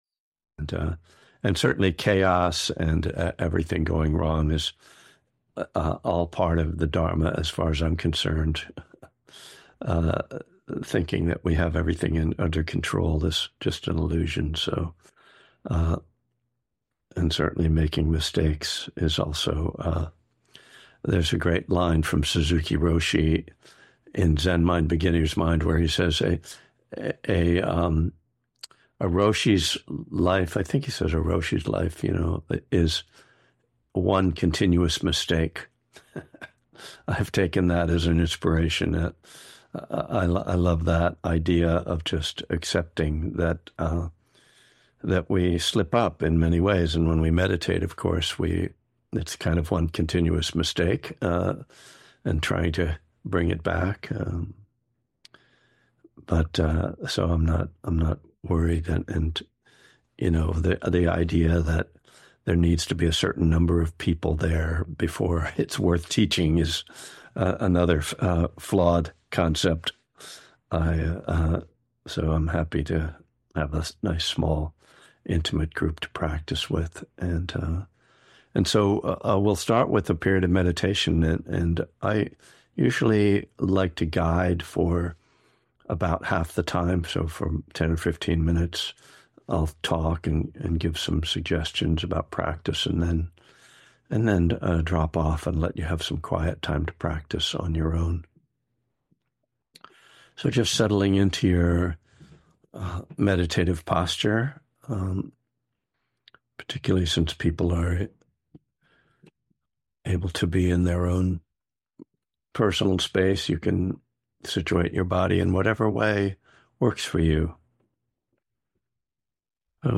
This talk will explore the connection between living a life of integrity and developing spiritual awakening